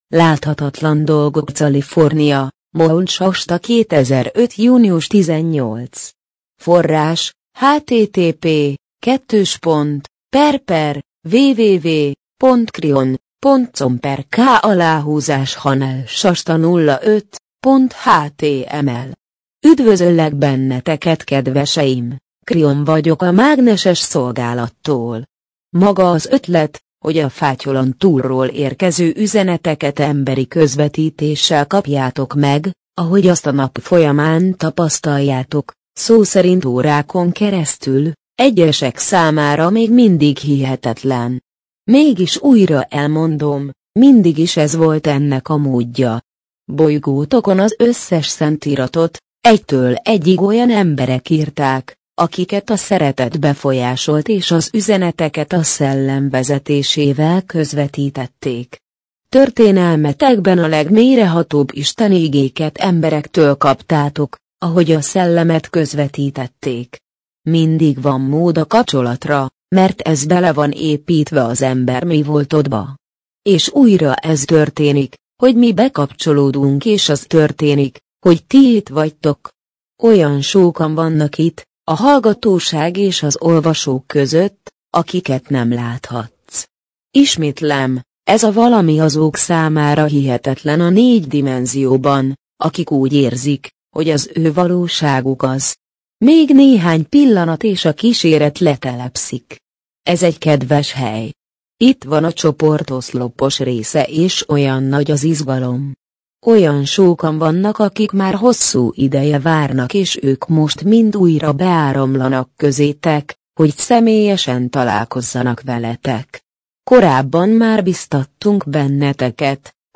MP3 gépi felolvasás Láthatatlan dolgok Láthatatlan dolgok California, Mount Shasta - 2005 június 18.